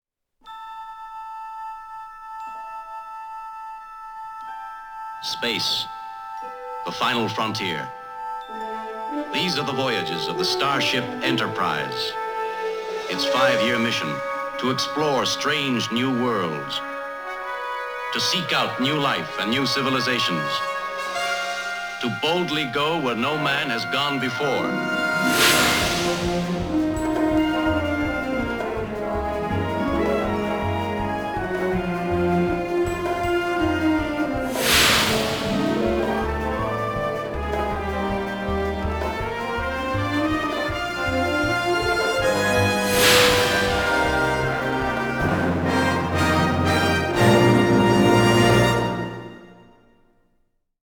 Opening & theme music